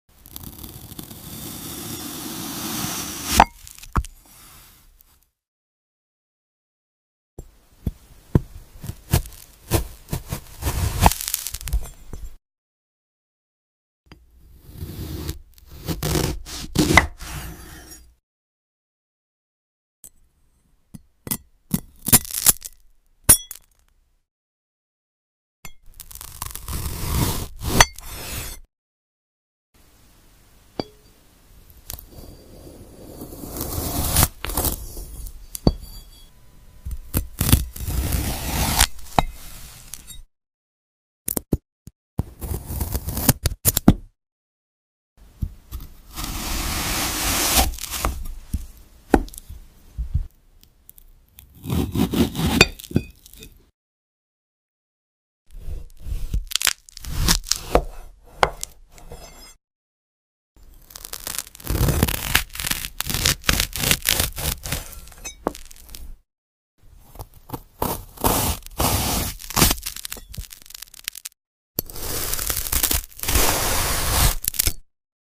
✨ Satisfying AI ASMR ✨ sound effects free download
Watch as perfectly cut glass apples fall into place 🍏🔪 Crystal-clear sounds, relaxing vibes, and pure perfection 🎧💎